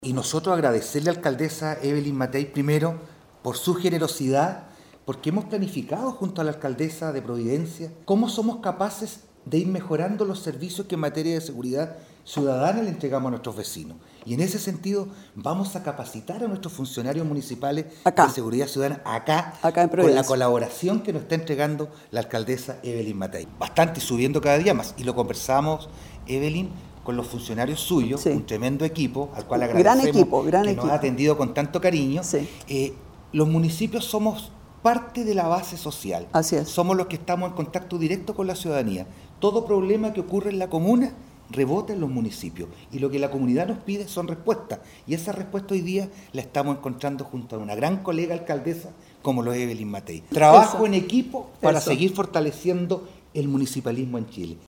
En este sentido, el alcalde Juan Eduardo Vera, quien estuvo acompañado por el concejal Jorge Luis Bórquez, expresó su interés en replicar estas estrategias en Castro, reconociendo la importancia de adoptar medidas comprobadas para enfrentar los desafíos locales de seguridad:
ALCALDE-VERA.mp3